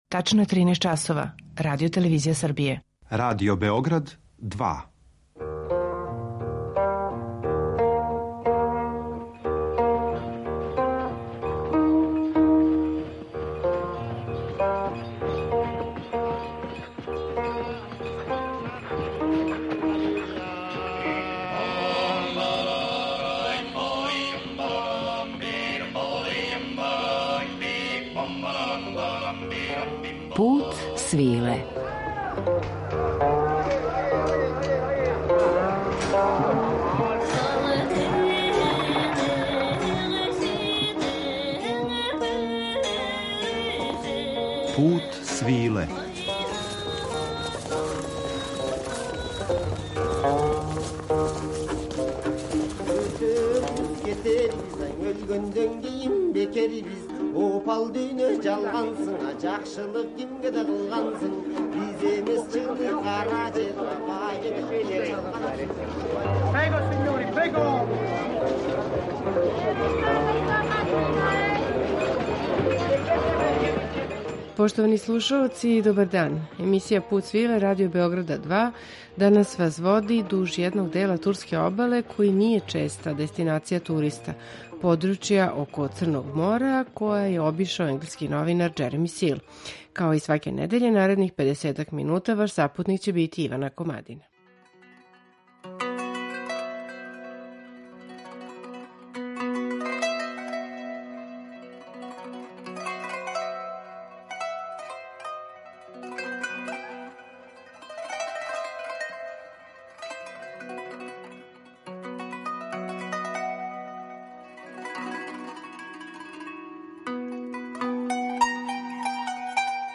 Пут свиле, као јединствено “радијско путовање”, недељом одводи слушаоце у неку од земаља повезаних са традиционалним Путем свиле, уз актуелна остварења из жанра “World music” и раритетне записе традиционалне музике.
Црноморску обалу Турске у данашњем Путу свиле обилазимо у друштву Марџана Дедеа, најзначајнијег модерног турског композитора и мултиинструменталисте.